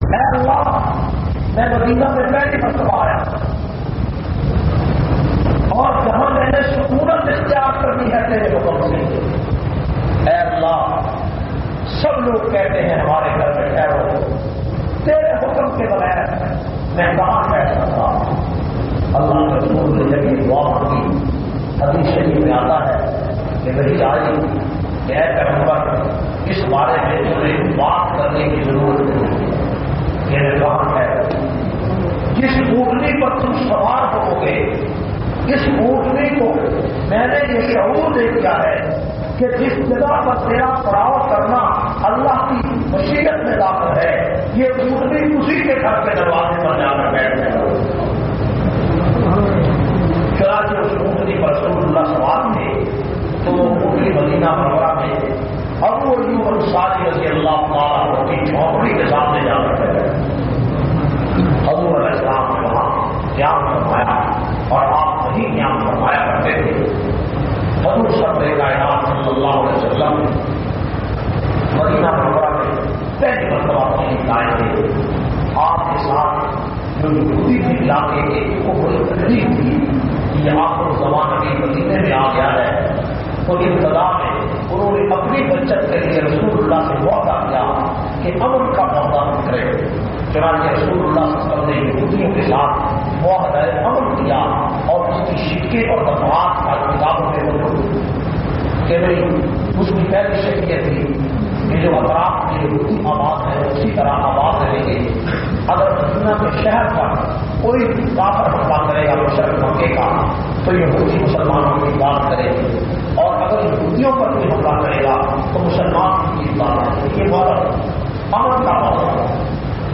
538- Shan e Risalat Jumma khutba Jamia Masjid Muhammadia Samandri Faisalabad.mp3